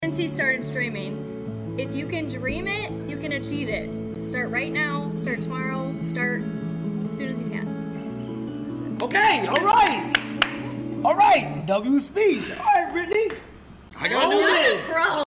a motivational speech on Kai Cenat MAFIATHON